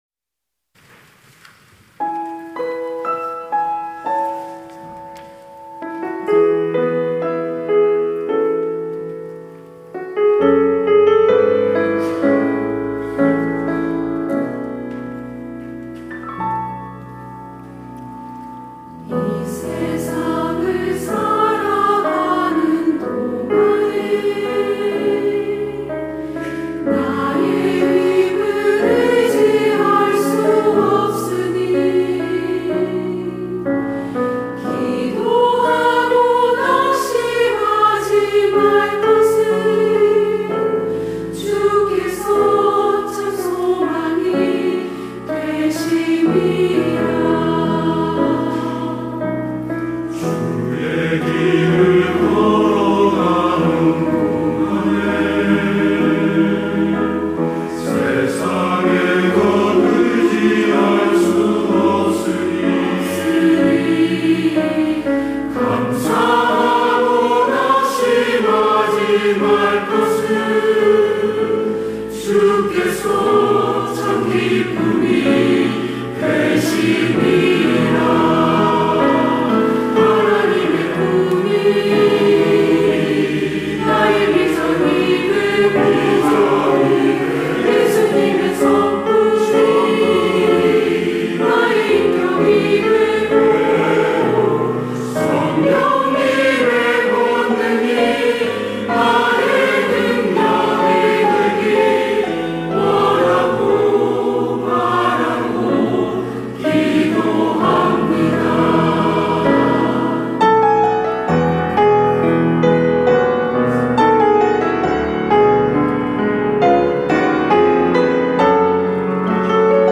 시온(주일1부) - 원하고 바라고 기도합니다
찬양대